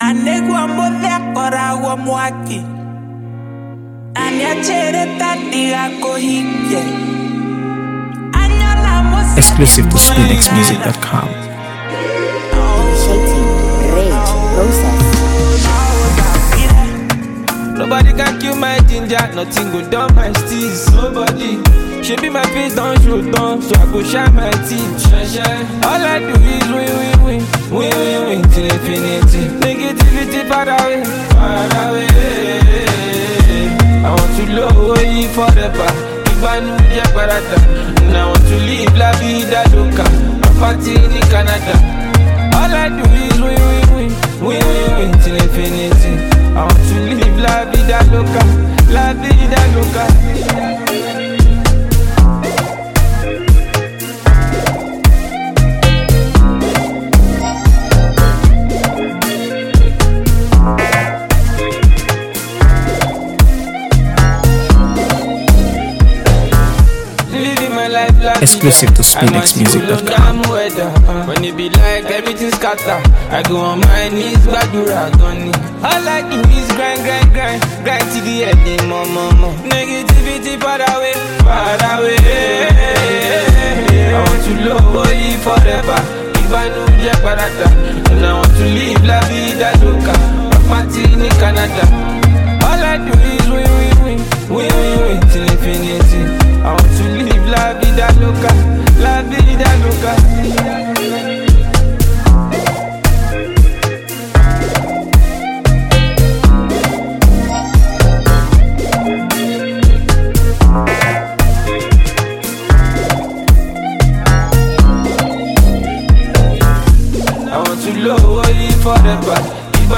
AfroBeats | AfroBeats songs
Full of a special mix of Afrobeats, Afro-pop